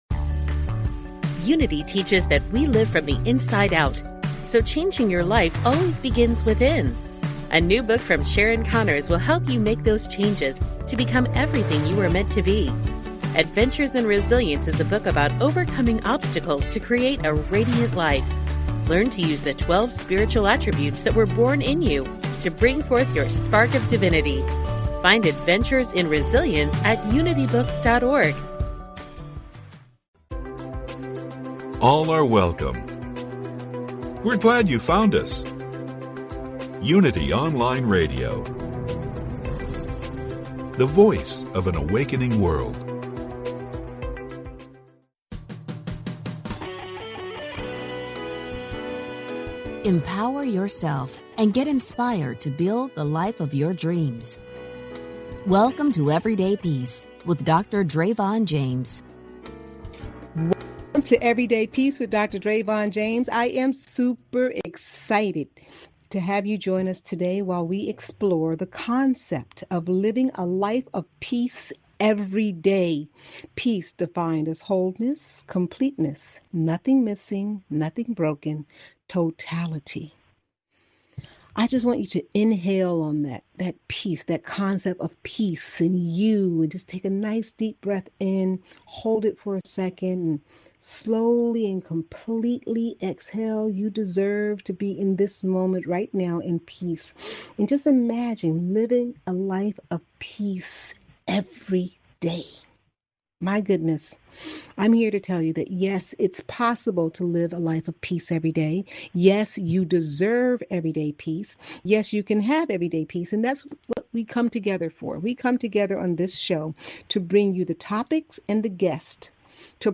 Please enjoy this encore broadcast from November 9, 2020.